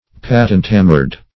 Patent-hammered \Pat"ent-ham"mered\, a.
patent-hammered.mp3